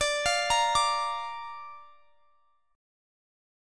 Listen to Dm#5 strummed